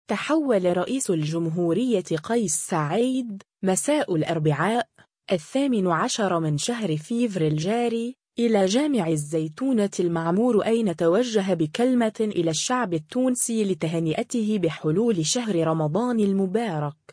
تحول رئيس الجمهوريّة قيس سعيّد، مساء الأربعاء، الثامن عشر من شهر فيفري الجاري، إلى جامع الزيتونة المعمور أين توجّه بكلمة إلى الشّعب التونسي لتهنئته بحلول شهر رمضان المبارك.